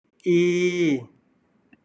/ ii /